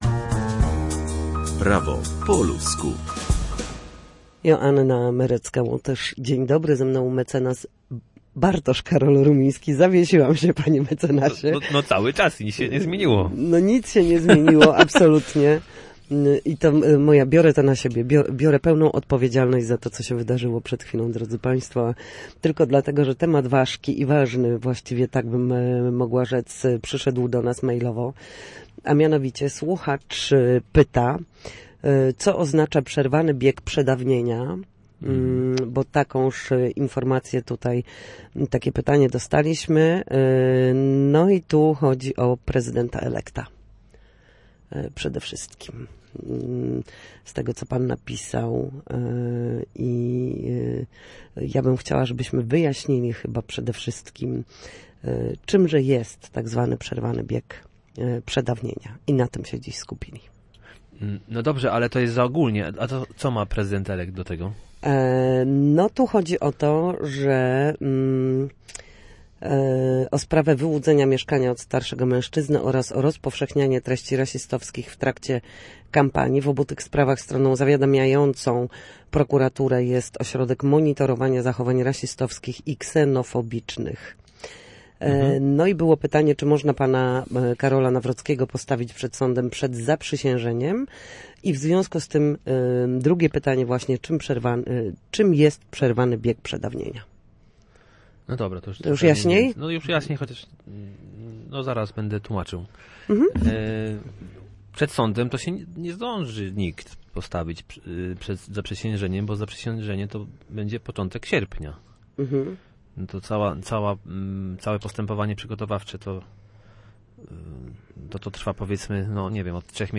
W każdy wtorek o godzinie 13:40 na antenie Studia Słupsk przybliżamy Państwu meandry prawa. Nasi goście – prawnicy i eksperci – odpowiadają na jedno, konkretne pytanie dotyczące postępowania przed sądem lub podstawowych zagadnień prawniczych.